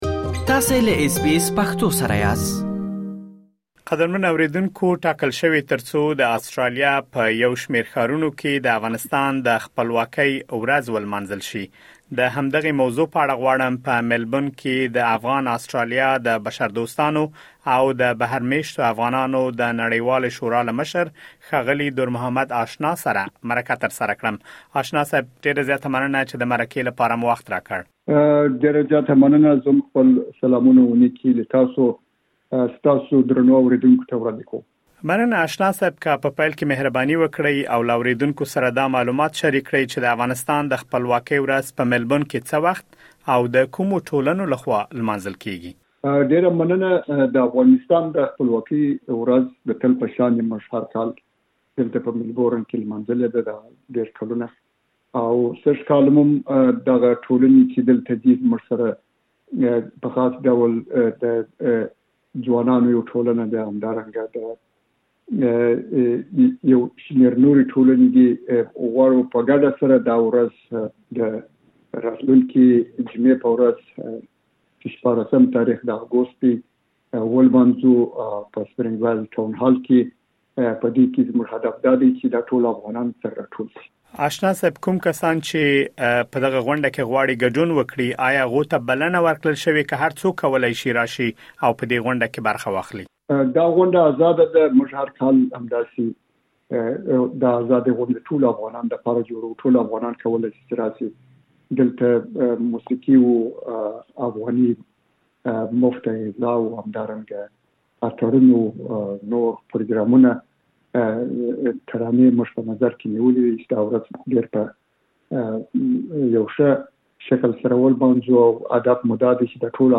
لا ډېر معلومات په ترسره شویو مرکو کې اورېدلی شئ.